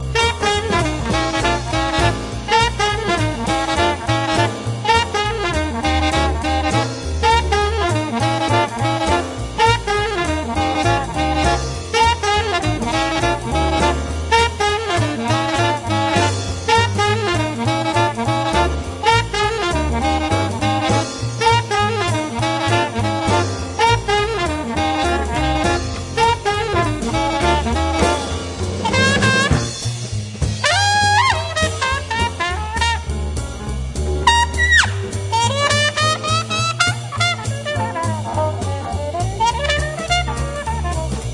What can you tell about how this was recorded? Recorded at the Red Gables Studio, September 26th 2006